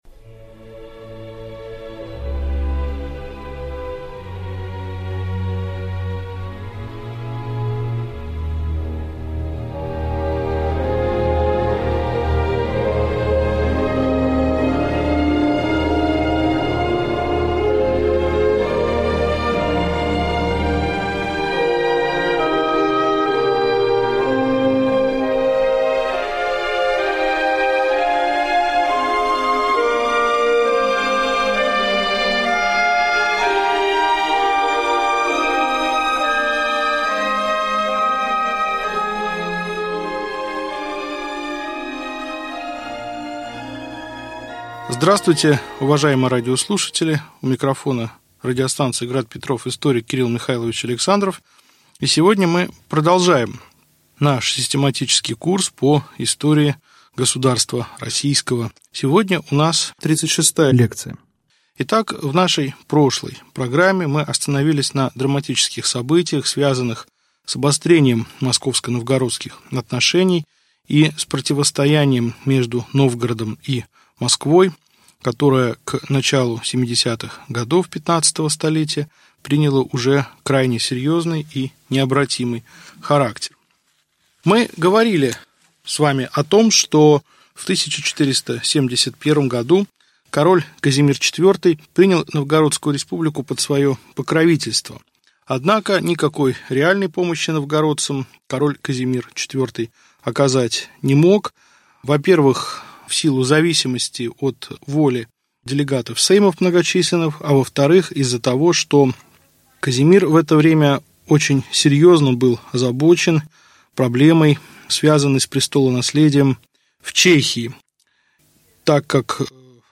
Аудиокнига Лекция 36. Присоединение Новгорода Великого к Москве | Библиотека аудиокниг